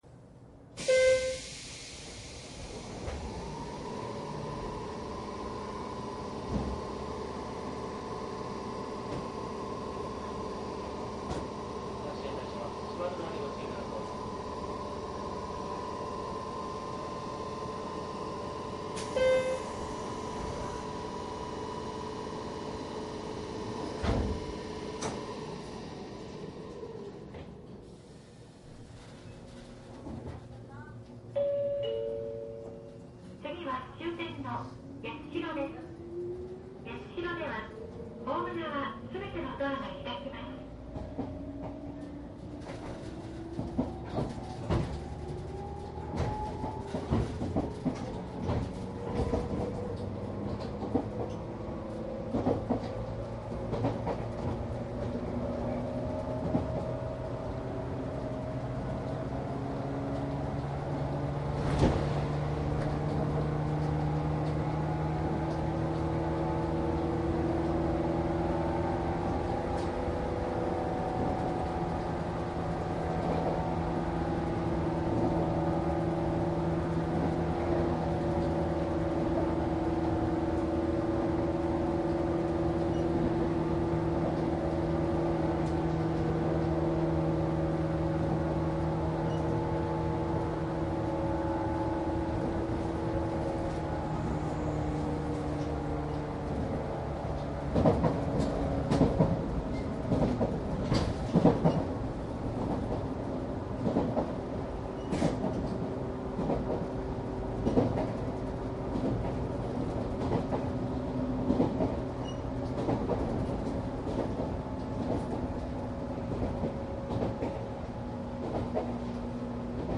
九州717系900番台 走行音 CD
■【ワンマン】熊本→八代  クモハ717-901／ クモハ716-901
サンプル音声 クモハ716-901..mp3
マスター音源はデジタル44.1kHz16ビット（マイクＥＣＭ959）で、これを編集ソフトでＣＤに焼いたものです。